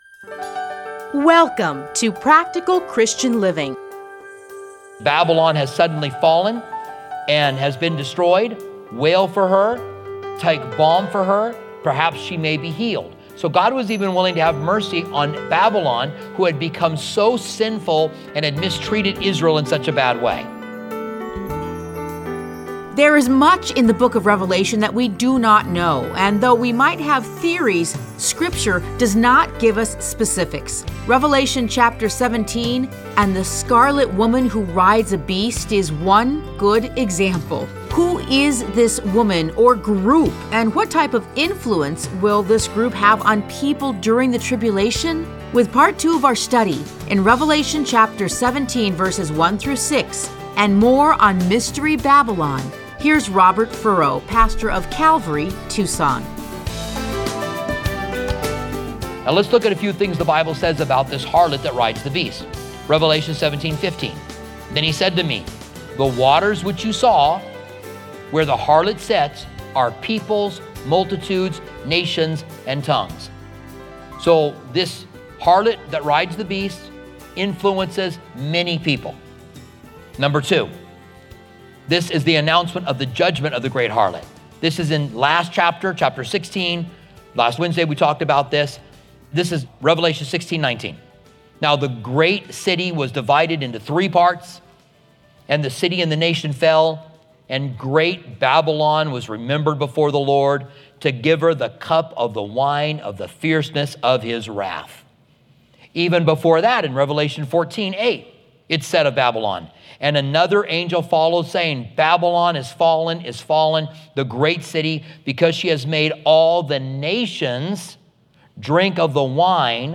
Listen to a teaching from Revelation 17:1-6.